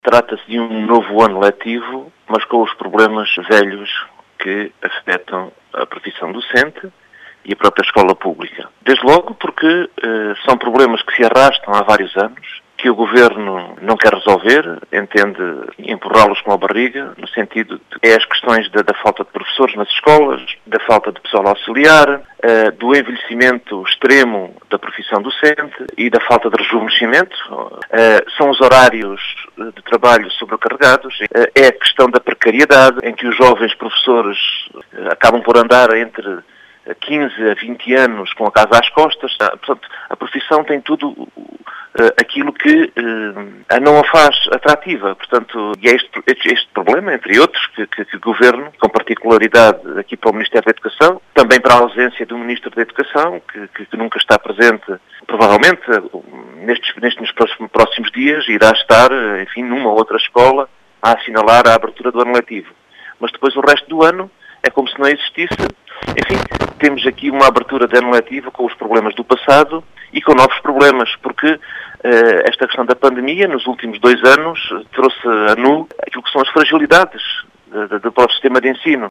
Em declarações à Rádio Vidigueira